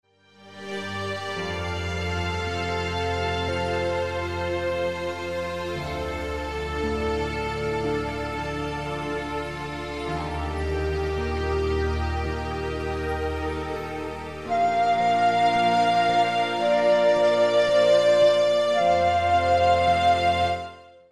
Wszystkie utwory na płycie są ze sobą połączone.